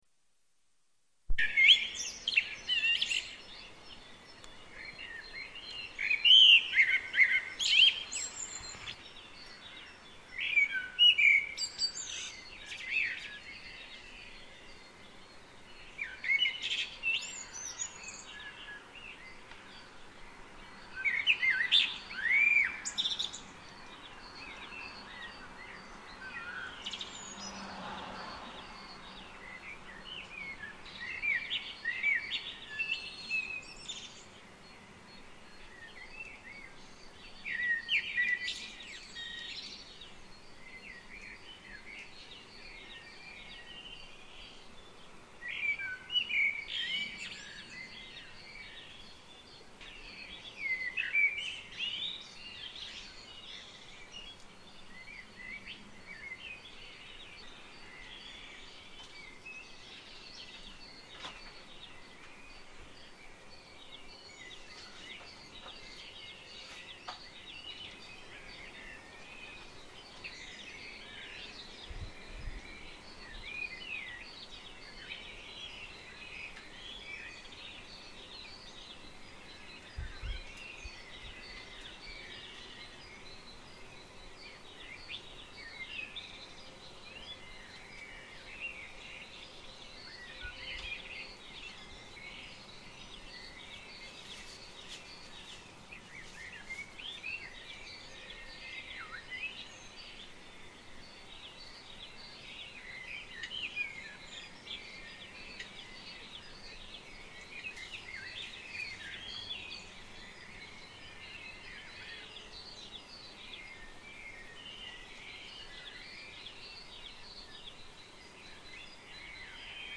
pajaros-por-la-manana.mp3